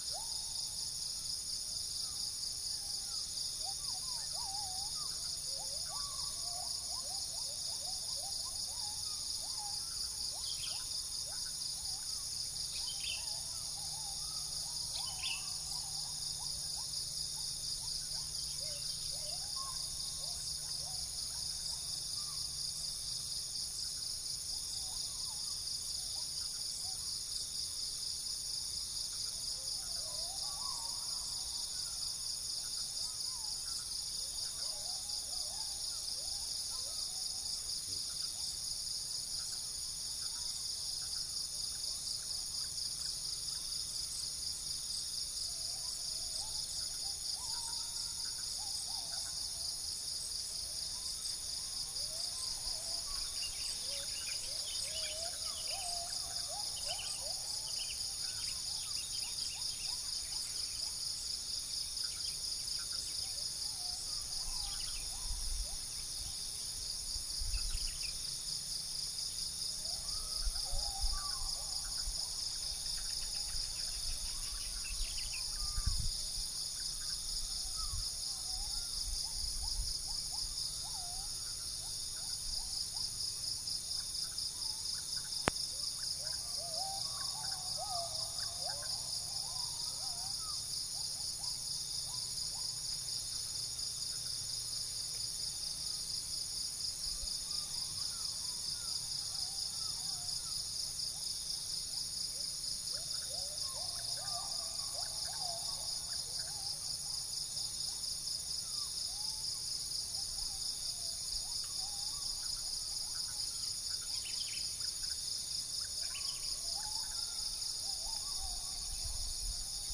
Actenoides concretus
Dicrurus paradiseus
Cyanoderma rufifrons
Orthotomus sericeus
biophony
Pycnonotus aurigaster
Trichastoma malaccense
Brachypodius atriceps
Picus puniceus